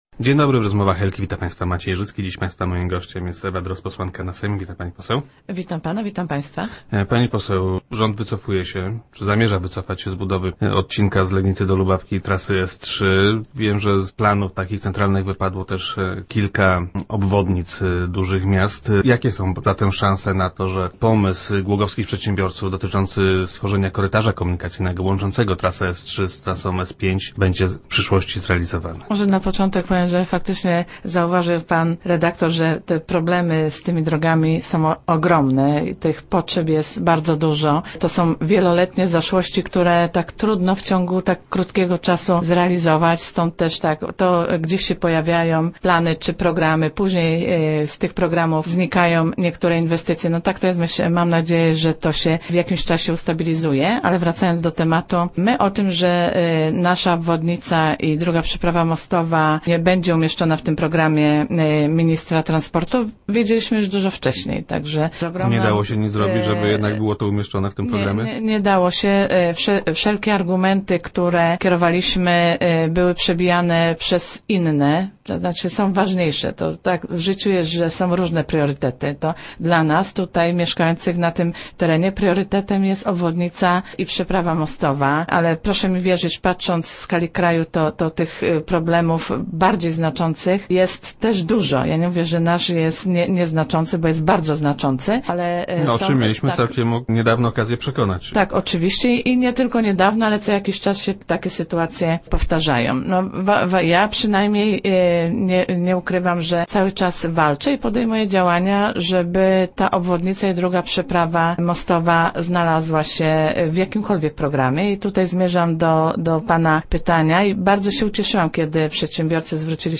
Jakie są więc szansę na realizację pomysłu głogowskich przedsiębiorców, by na bazie drogi krajowej nr 12 powstał komunikacyjny korytarz, łączący drogę S3 z trasą S5? Gościem Rozmów Elki była posłanka Ewa Drozd.